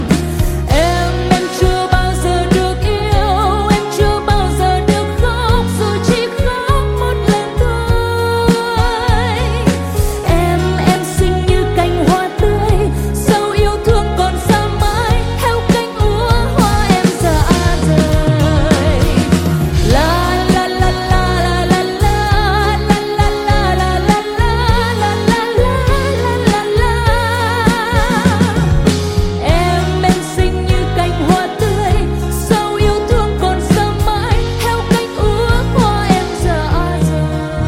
Thể loại: nhạc trẻ.